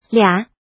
怎么读
liǎ liǎng
lia3.mp3